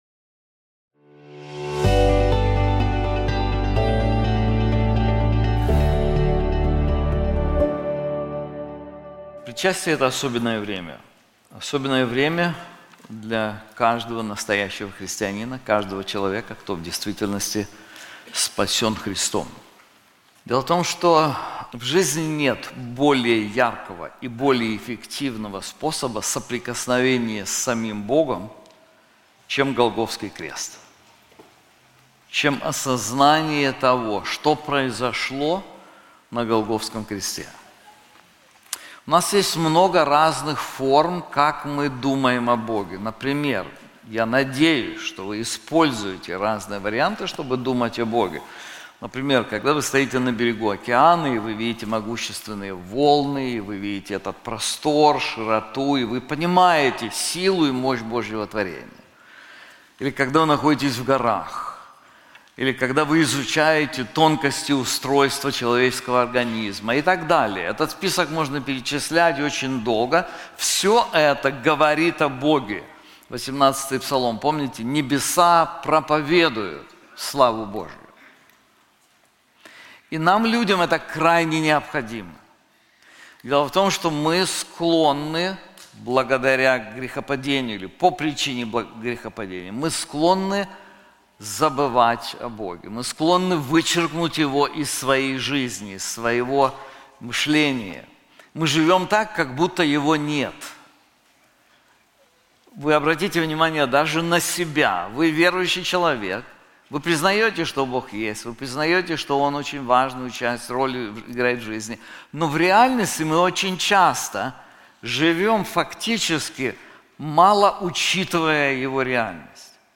This sermon is also available in English:Father Who Crushed the Son • Isaiah 53:10